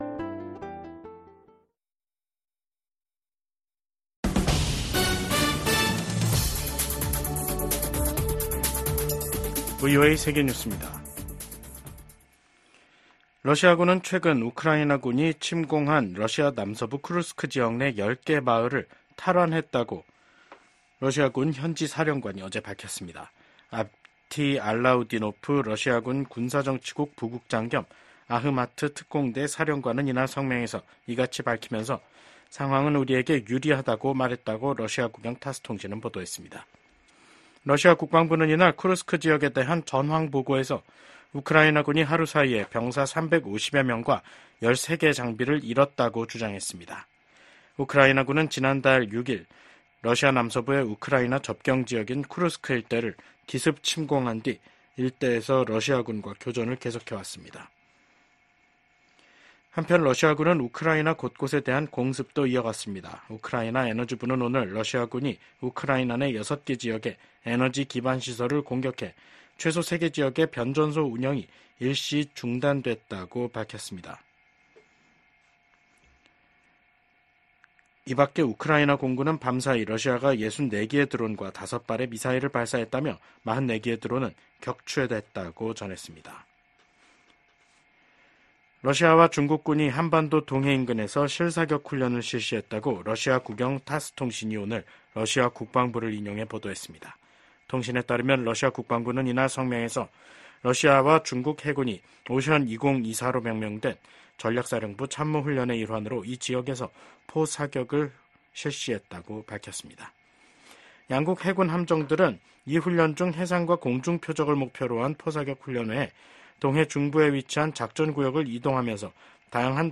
VOA 한국어 간판 뉴스 프로그램 '뉴스 투데이', 2024년 9월 12일 2부 방송입니다. 북한이 70여일 만에 또 다시 단거리 탄도미사일 도발에 나섰습니다.